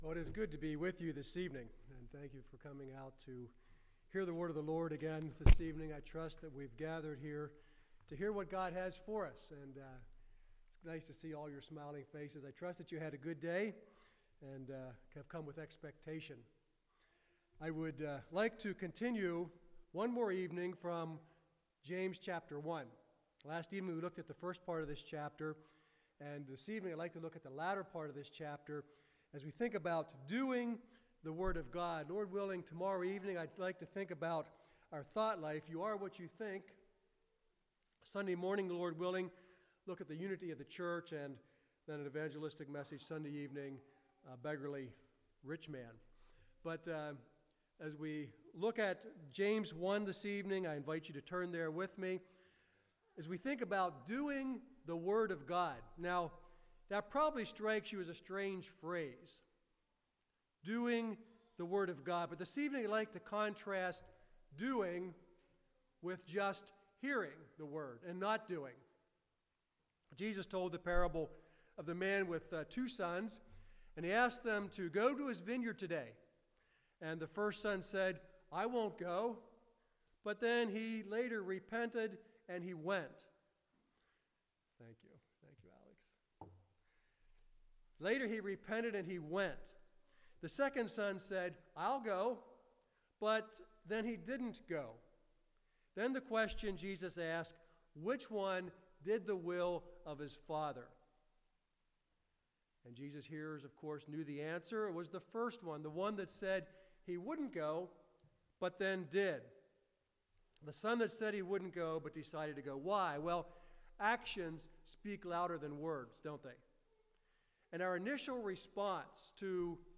Service Type: Revivals